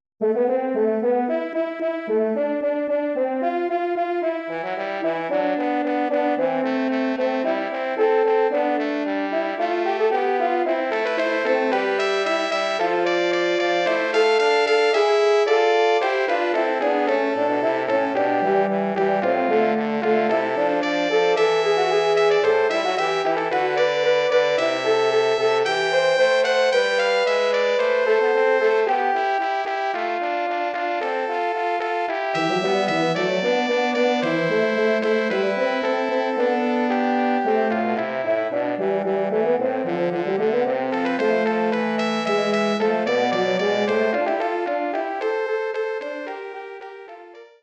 Bearbeitung für Blechbläsertrio
Besetzung: Trompete (B), Horn (F), Posaune
arrangement for brass trio
Instrumentation: trumpet (Bb), French horn (F), trombone